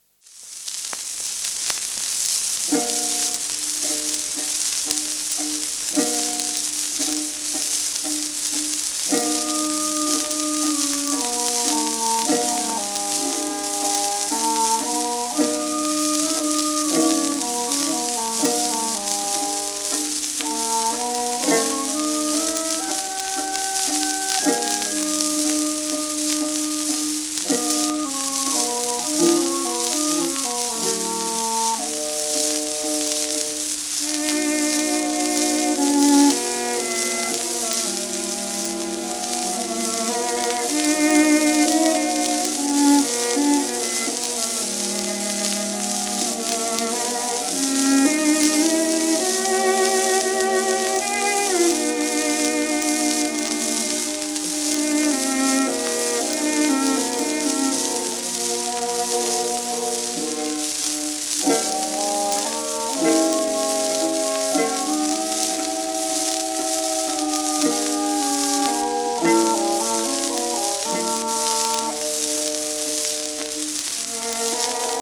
w/オーケストラ
10インチ片面盤
盤質A- *サーフェイスノイズ
1920年録音
旧 旧吹込みの略、電気録音以前の機械式録音盤（ラッパ吹込み）